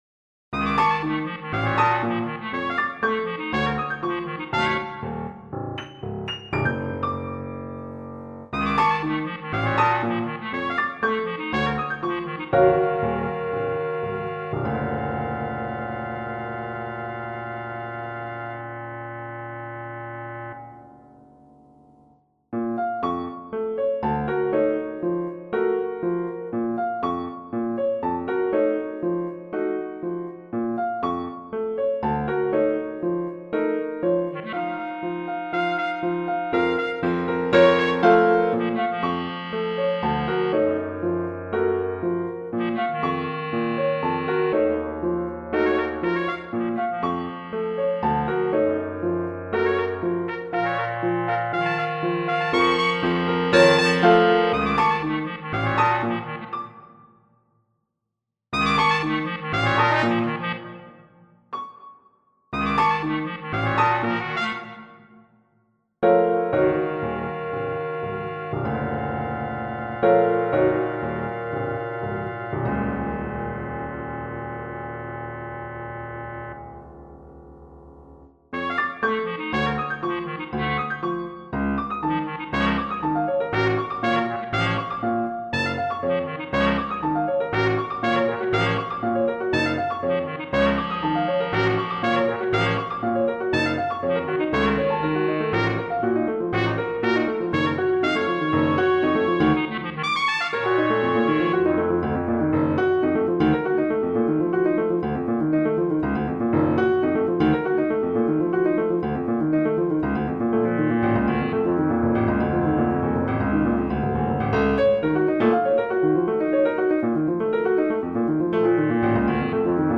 Scored for Piccolo Trumpet, Bass Clarinet and Piano